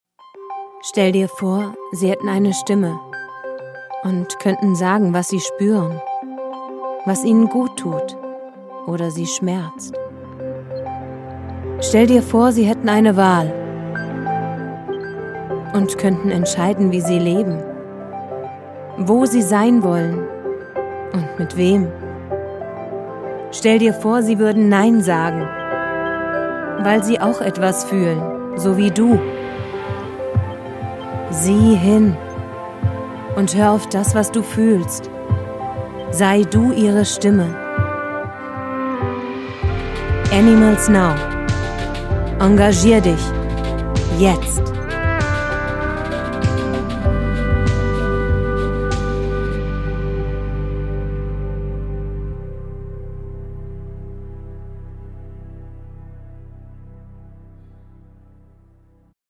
Hier ein paar Audio- und Video-Beispiele – von sinnlich, ernst oder sachlich über unbeschwert und heiter hin zu aufgebracht und verzweifelt.
Imagefilme, Produktvideos & Erklärfilme